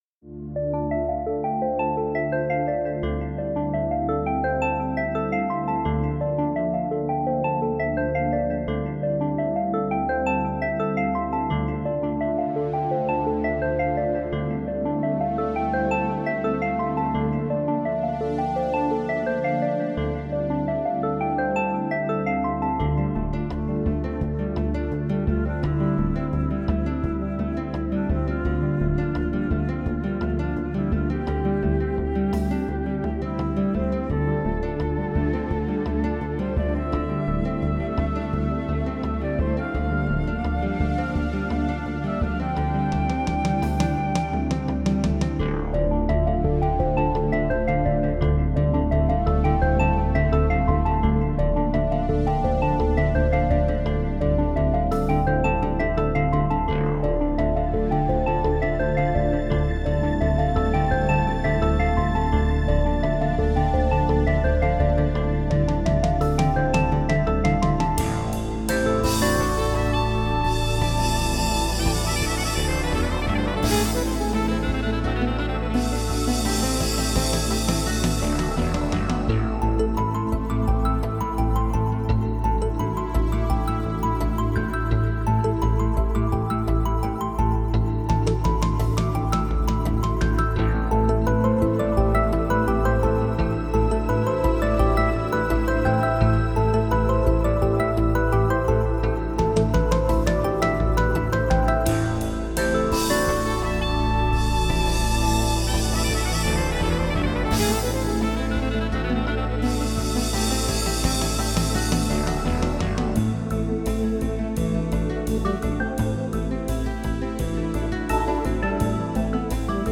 Audio with Lead-solo (part of the pack):